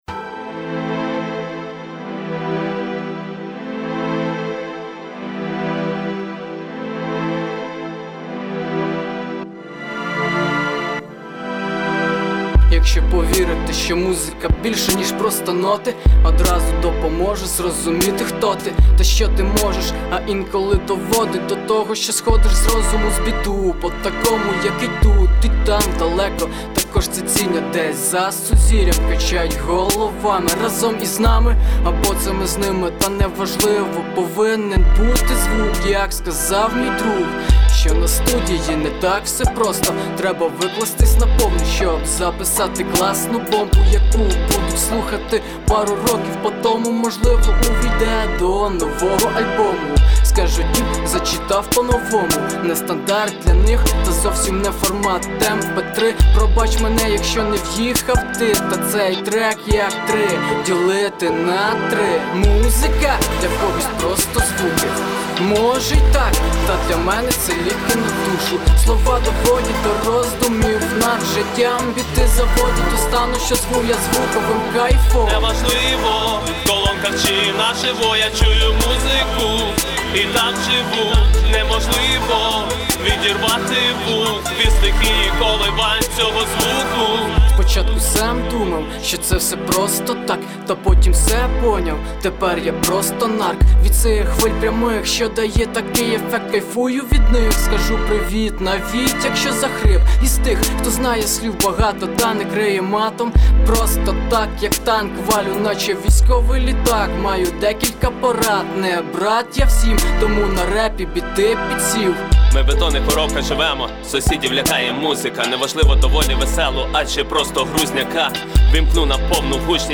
Рубрика: Поезія, Авторська пісня
приспів гарний =) 39 39 39